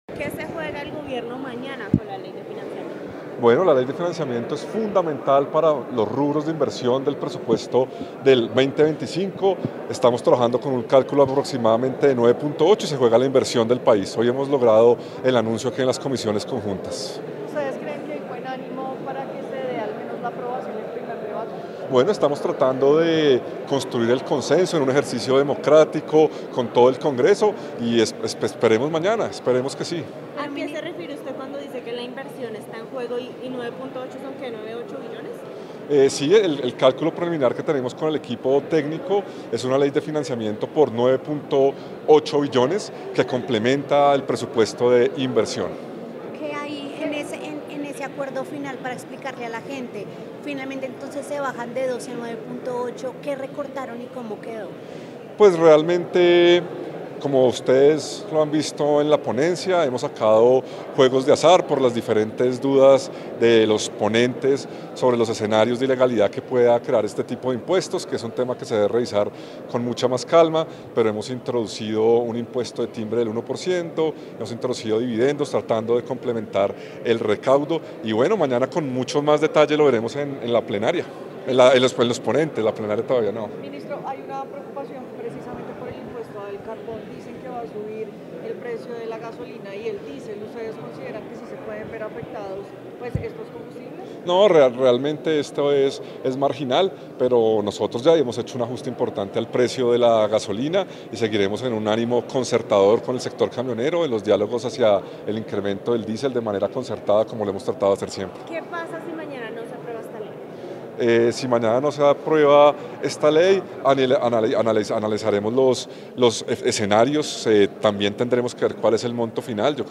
11. Declaración a Medios Ministro Diego Guevara, 10 de diciembre 2024
Stereo